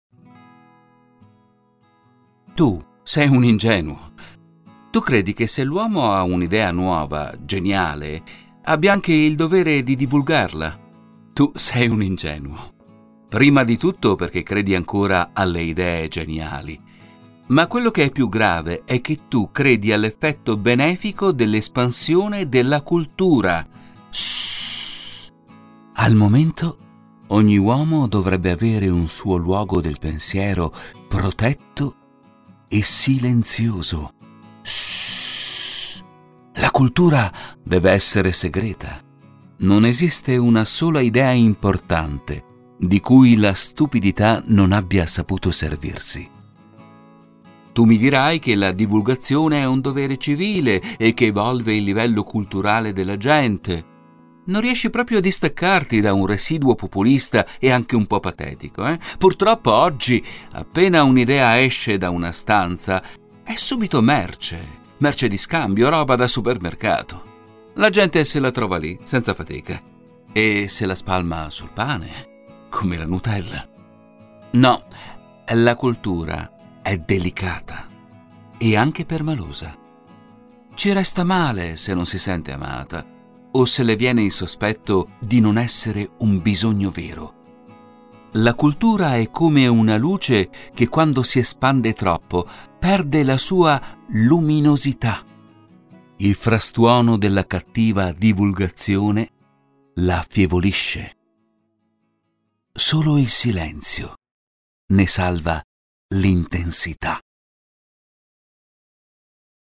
MONOLOGO